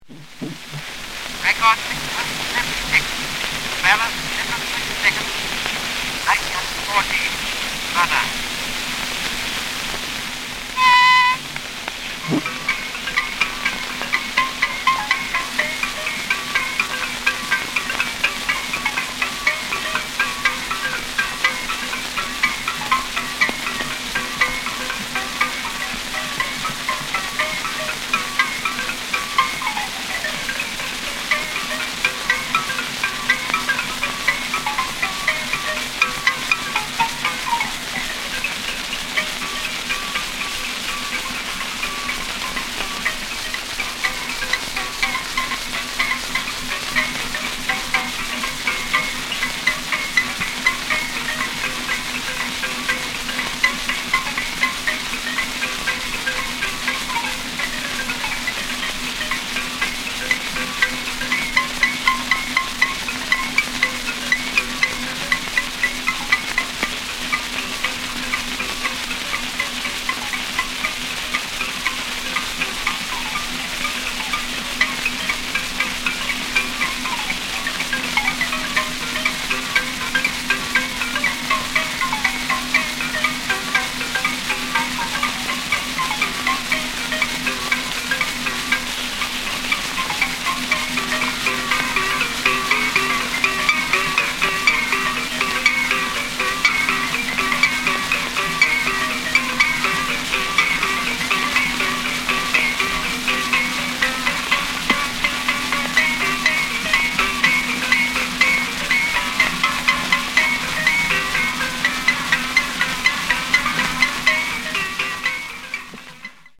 Balangi (xylophone) duet
From the sound collections of the Pitt Rivers Museum, University of Oxford, being from a collection of wax cylinder recordings of songs and spoken language